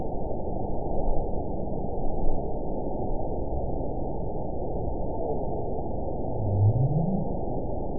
event 921717 date 12/17/24 time 22:41:43 GMT (11 months, 2 weeks ago) score 9.75 location TSS-AB02 detected by nrw target species NRW annotations +NRW Spectrogram: Frequency (kHz) vs. Time (s) audio not available .wav